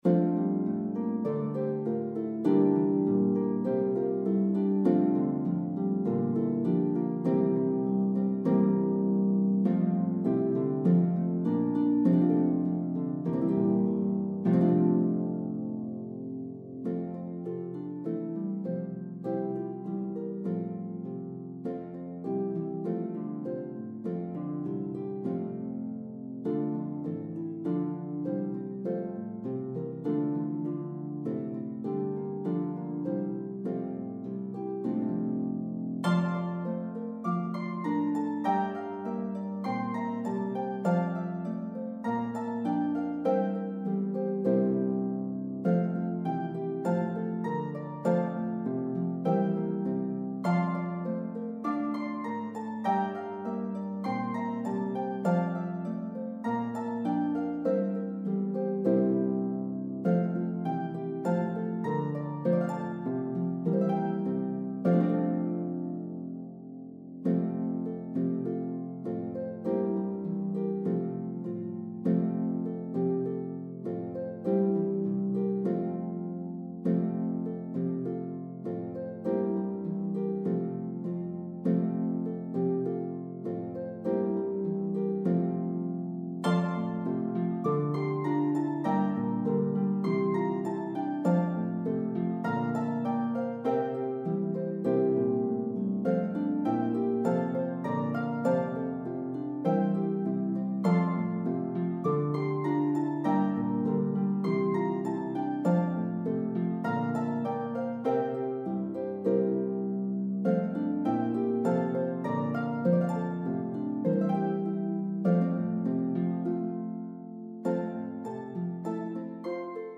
is for two lever or pedal harps
English and French carols